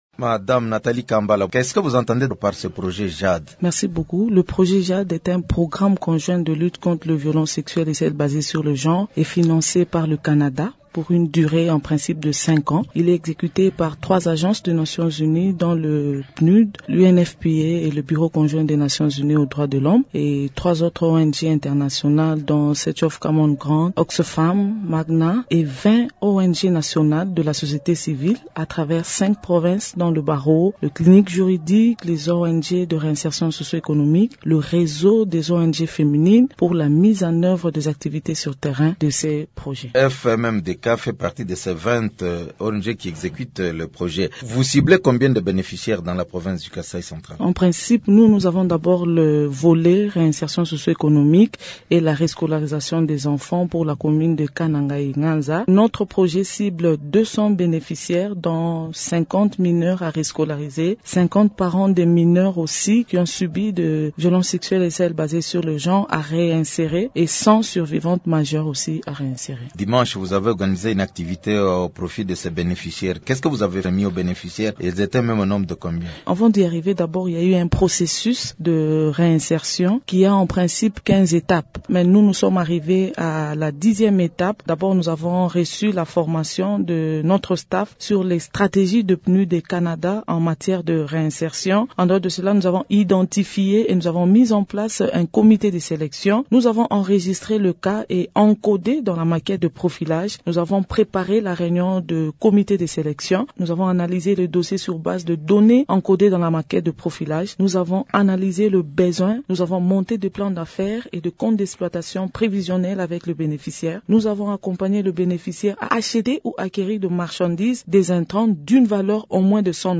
Elle s’entretient